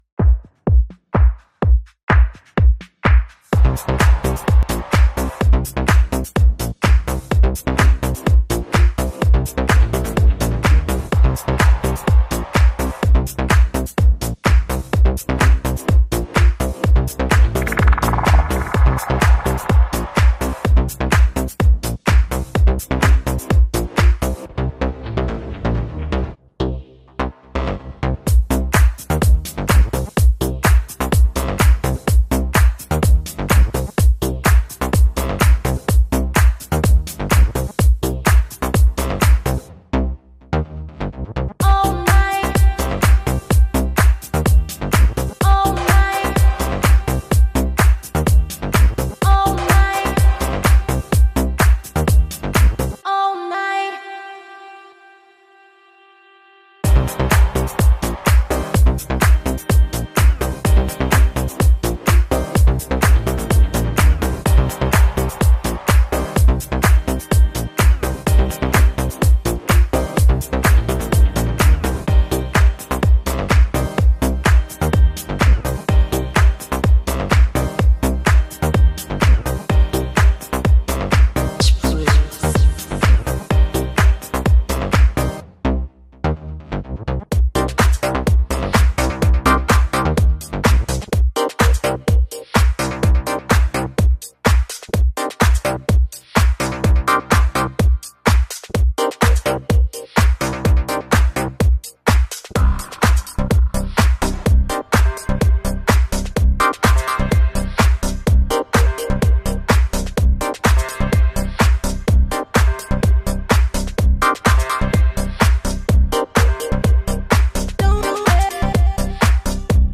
Ритмичная модная музыка